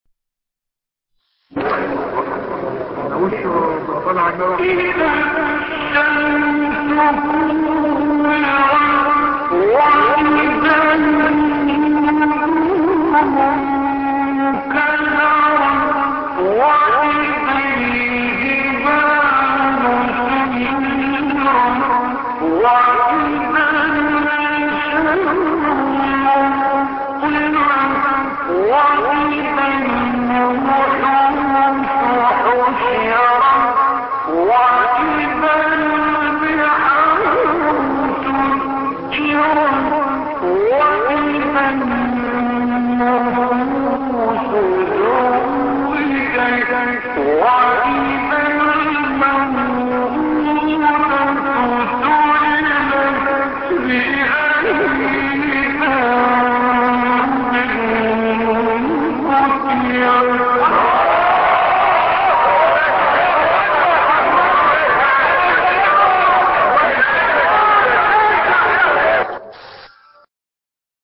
سورة التكوير ـ عبدالباسط ـ مقام العجم - لحفظ الملف في مجلد خاص اضغط بالزر الأيمن هنا ثم اختر (حفظ الهدف باسم - Save Target As) واختر المكان المناسب